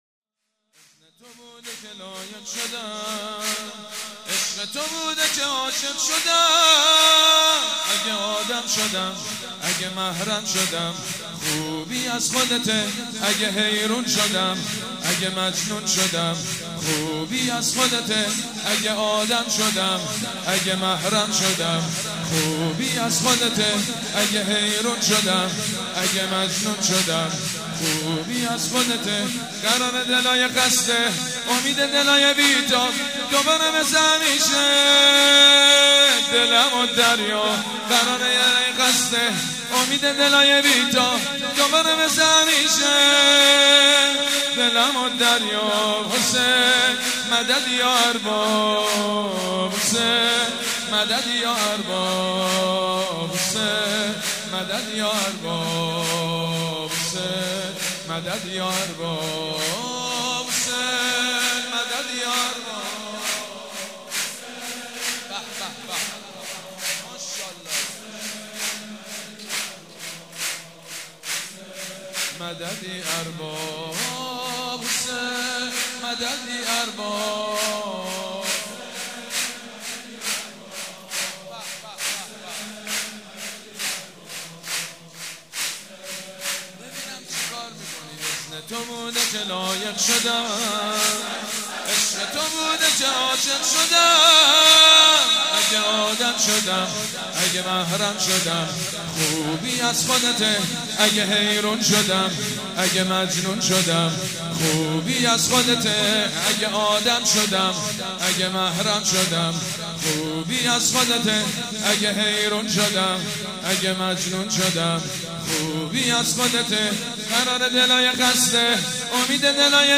سرود: عشق تو بوده که عاشق شدم